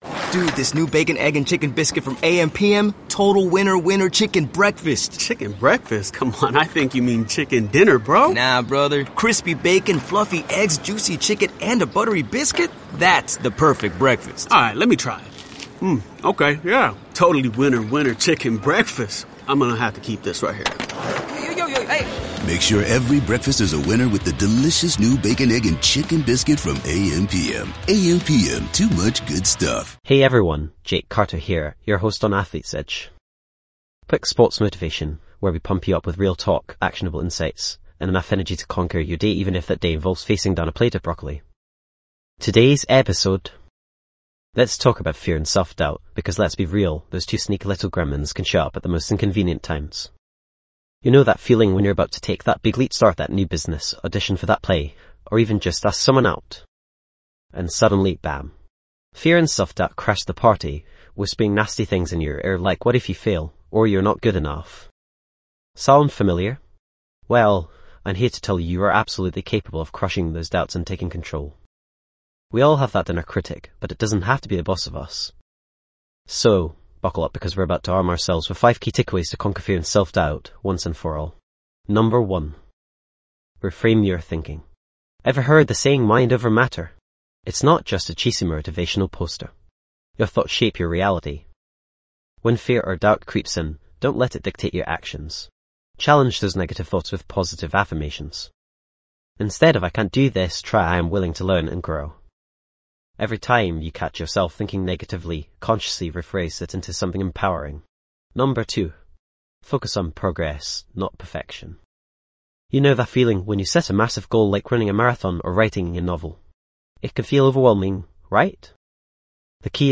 Podcast Category:. Sports & Recreation Motivational Talks
This podcast is created with the help of advanced AI to deliver thoughtful affirmations and positive messages just for you.